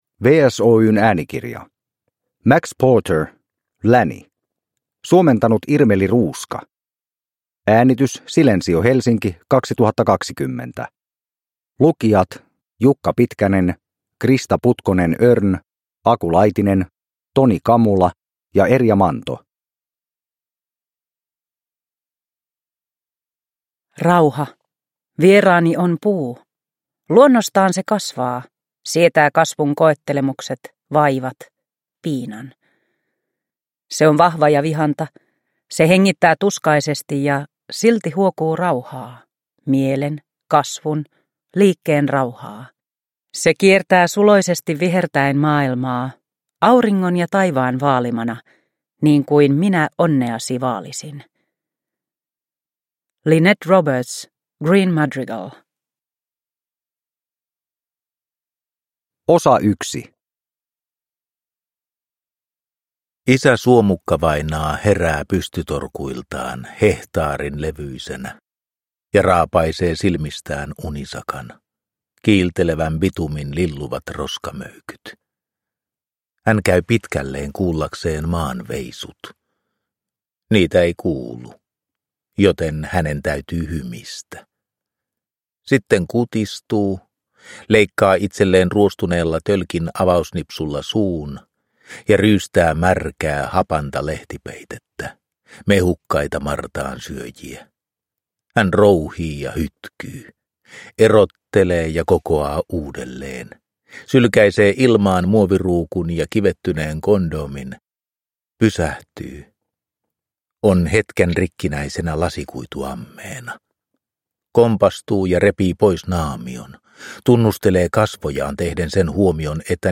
Lanny – Ljudbok – Laddas ner